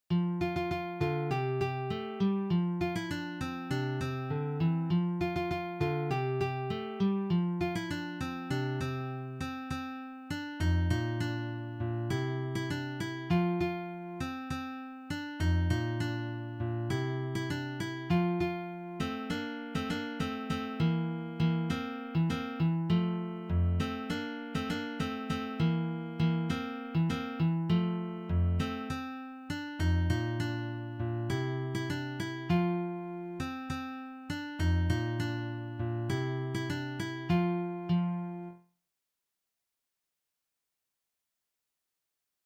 leicht arrangiert für Gitarre
Gitarre (1)